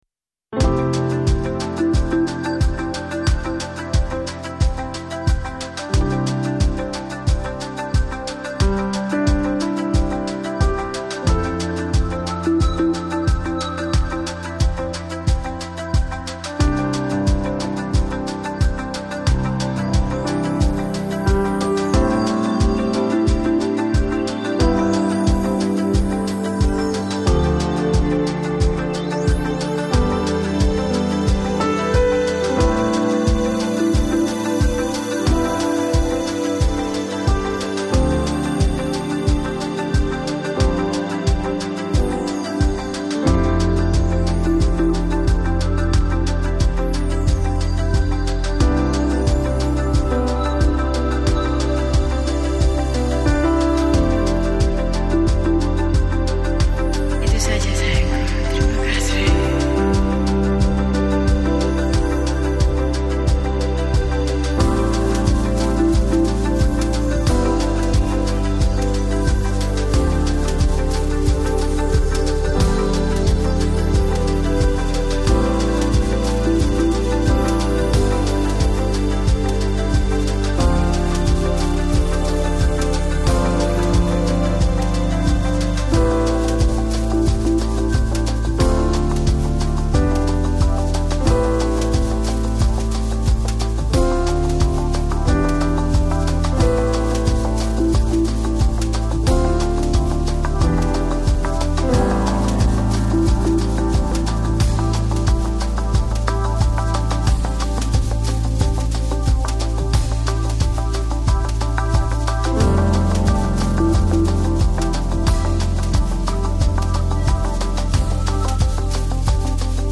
Lo-Fi, Lounge, Chillout, Downtempo Год издания